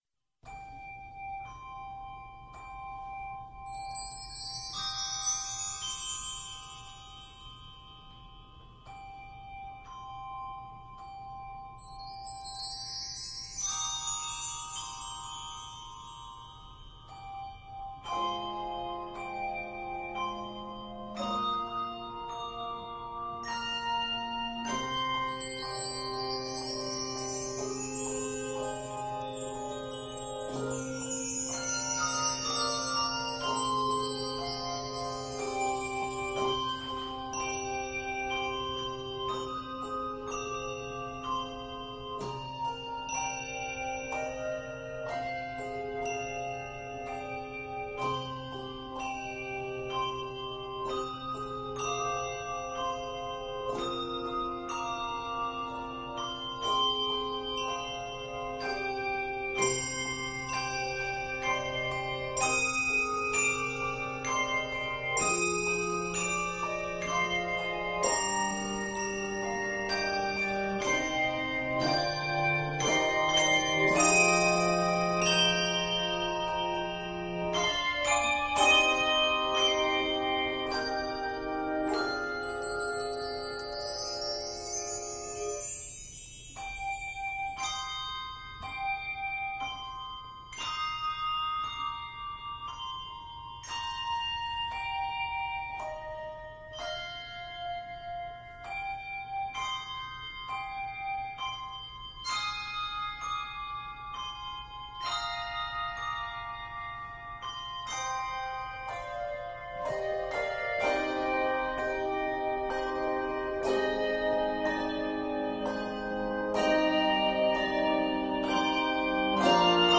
It is scored in c minor and is 50 measures in length.